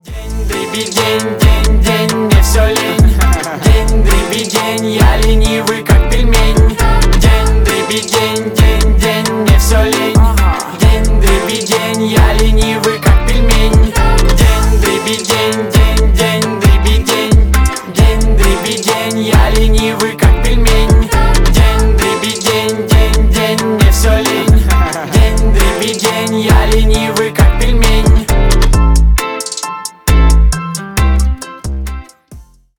• Качество: 320 kbps, Stereo
Рэп и Хип Хоп
весёлые